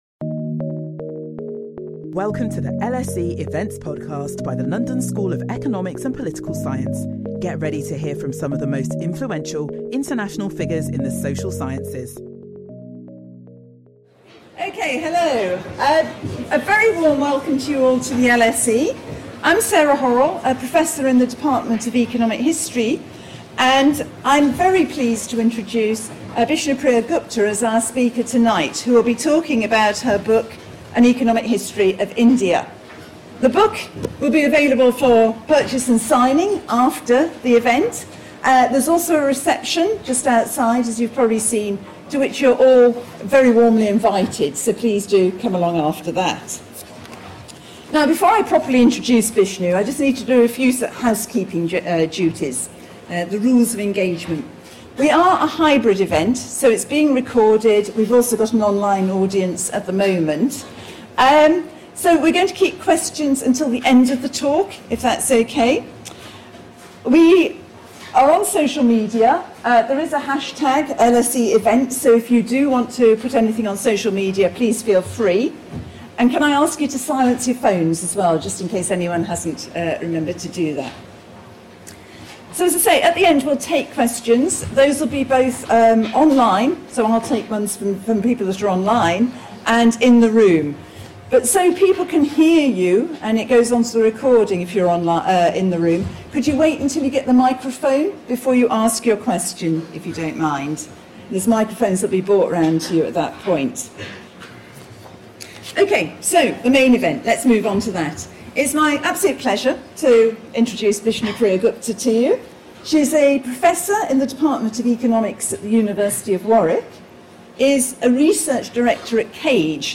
which she will discuss in this public event.